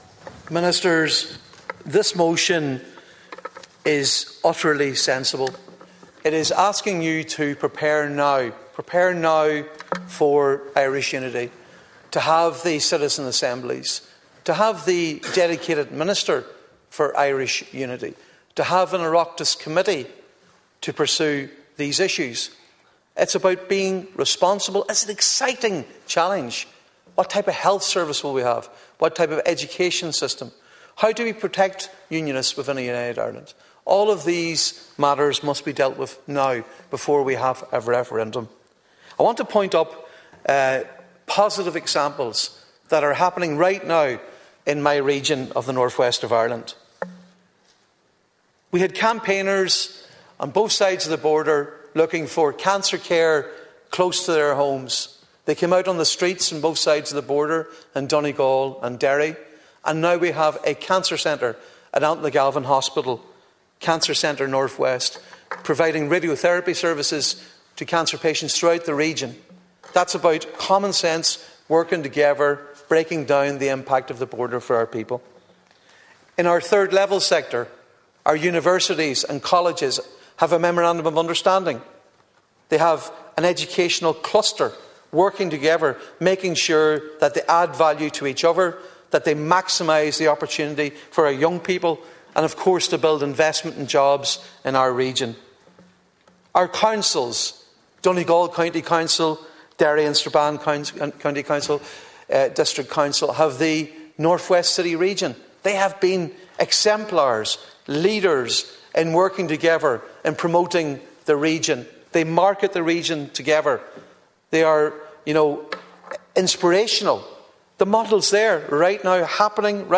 Donegal Deputy Padraig MacLochlainn told a debate on Irish Unity this week that cooperation in the areas of health, education and business show what can be achieved when people and communities work together.